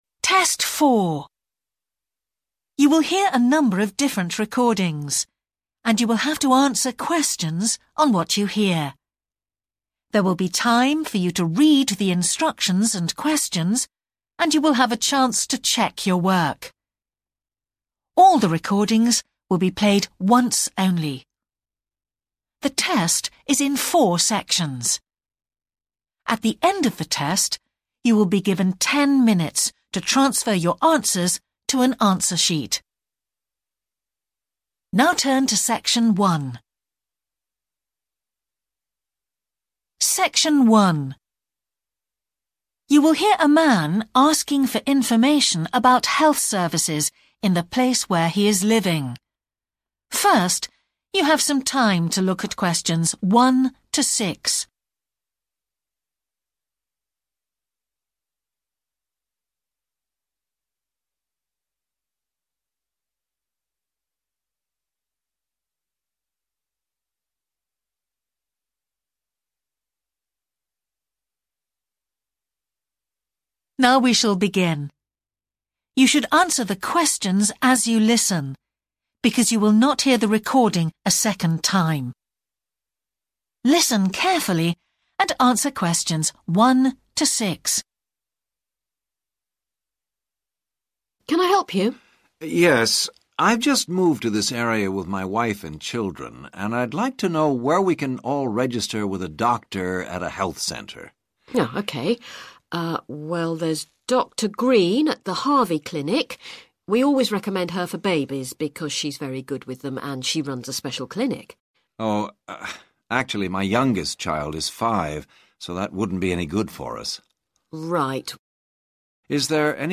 Each section challenges your ability to understand detailed conversations, follow discussions, and interpret information effectively.